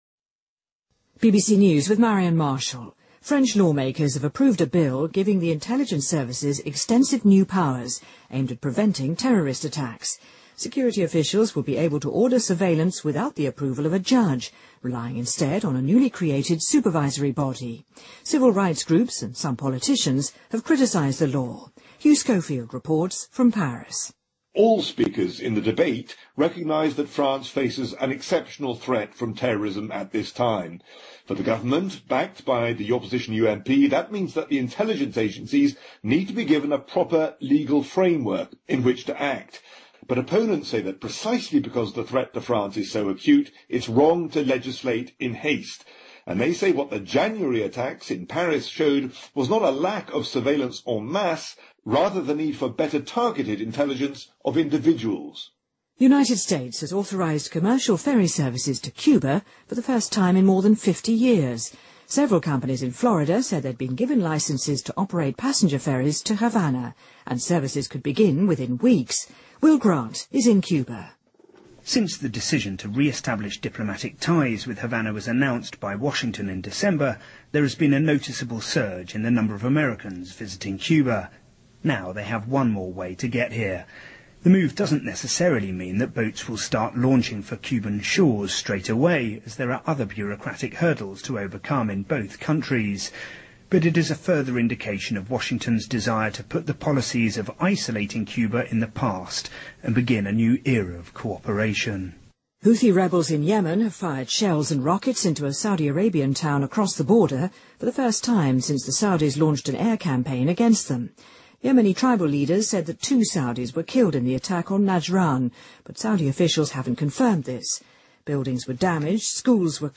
BBC news,美国已经授权对古巴进行商业渡轮服务